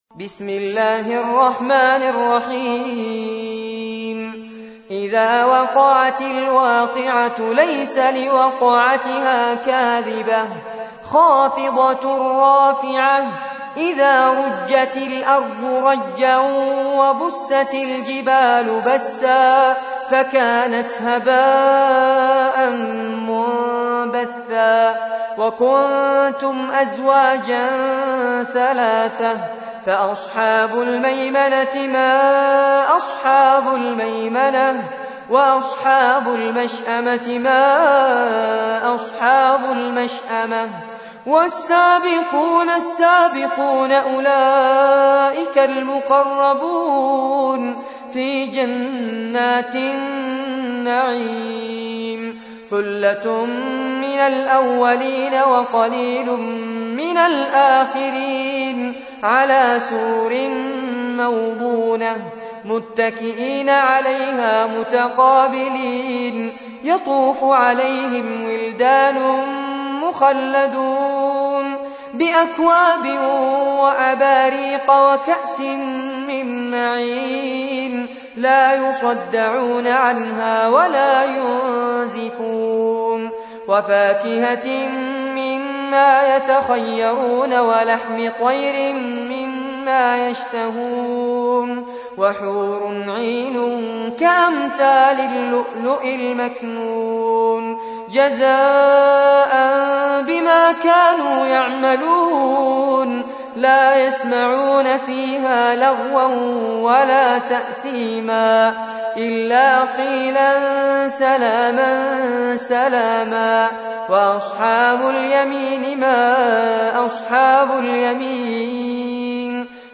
تحميل : 56. سورة الواقعة / القارئ فارس عباد / القرآن الكريم / موقع يا حسين